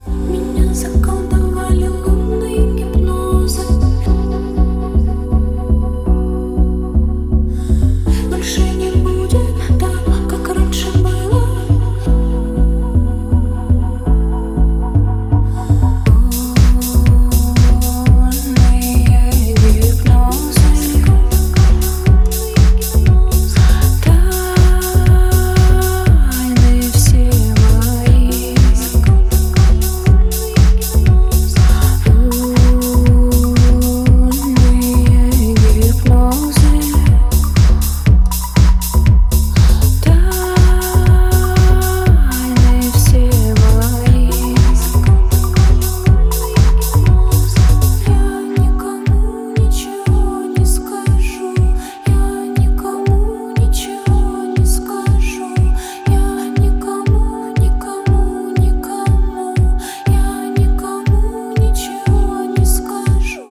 • Качество: 128, Stereo
атмосферные
спокойные
indie pop
Завораживающие
магические
таинственные
космическая музыка